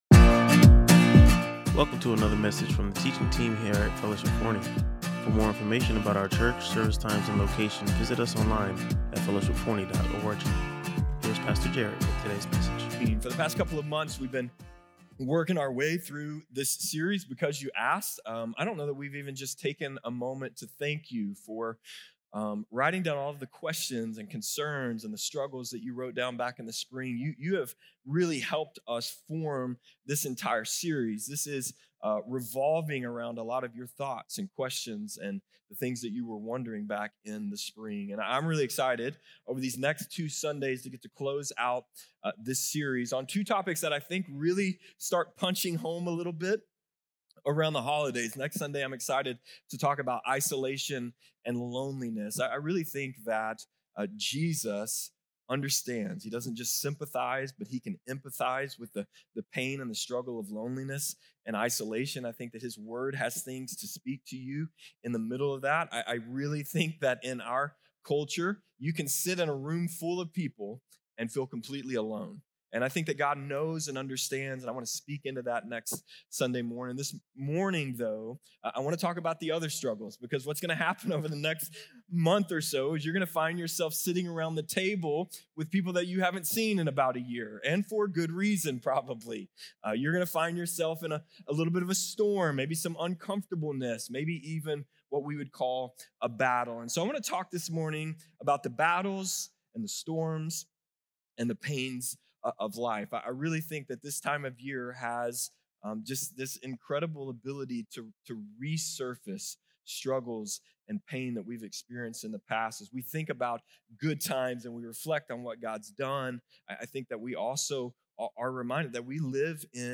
He stressed that victory isn’t about circumstances, but about remaining faithful to God throughout the journey. Using Mark 4’s account of Jesus calming the storm, he reminded the congregation that God’s presence, rather than immediate solutions, is the ultimate promise to believers.